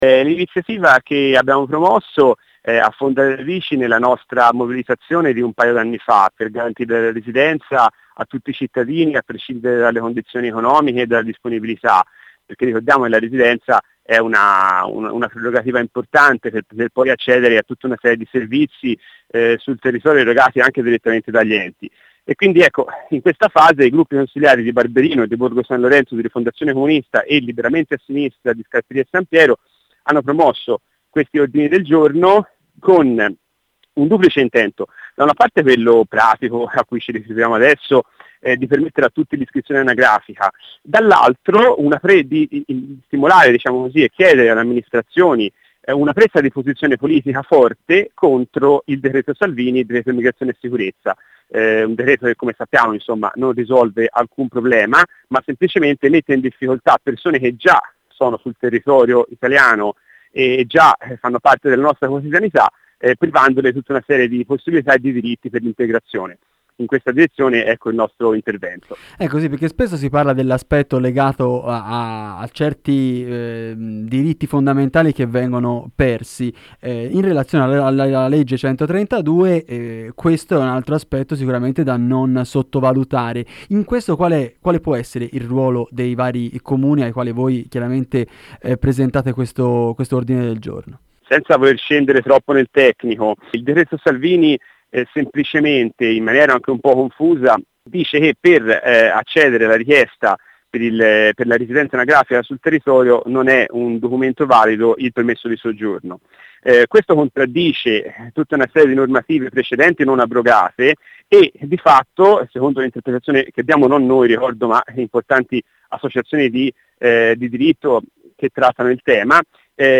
Qua di seguito l’intervista radio